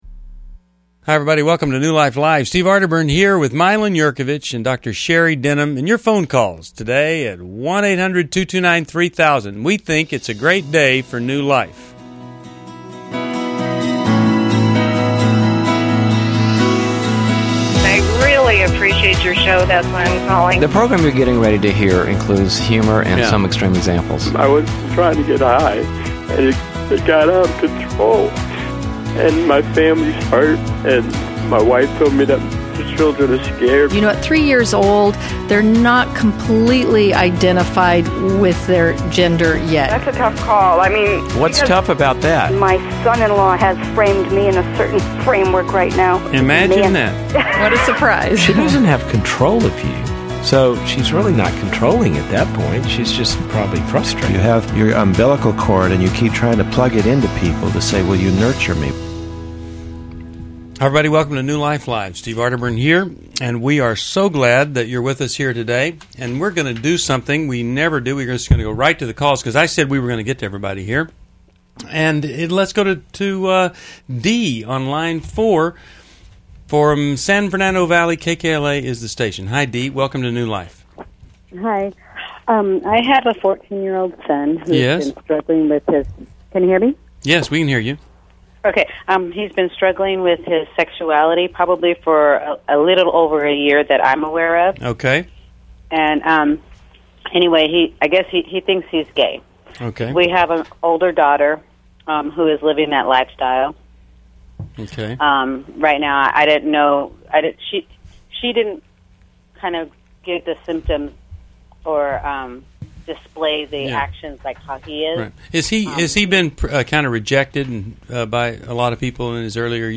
New Life Live: November 3, 2011 - Hosts tackle parenting, sexuality, and relationship dilemmas, addressing callers on topics from affection to legalism.